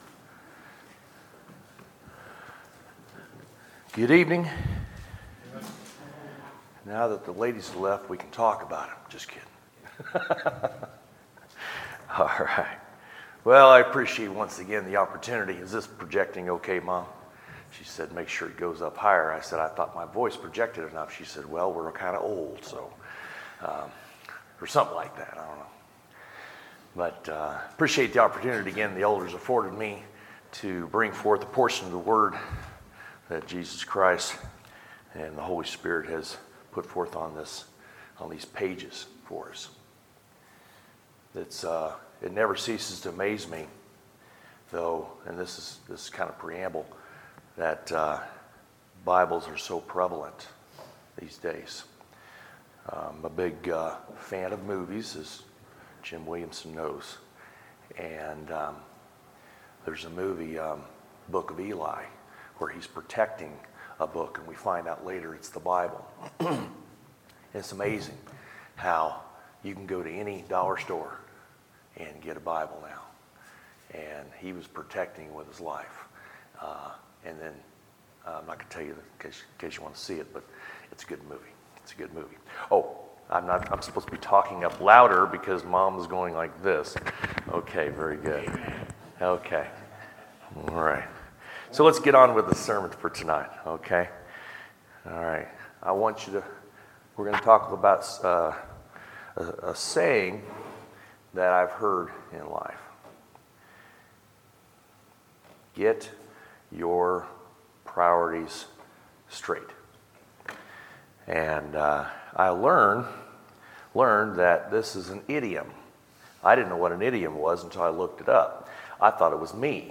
Sermons, July 1, 2018